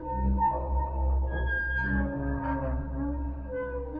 fan_squeaks_1.ogg